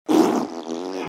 Farting Noises Sfx